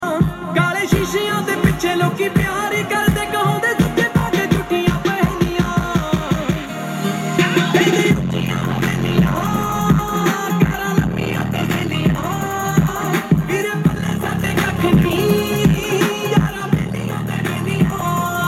6 Inches Woofer Speaker With Sound Effects Free Download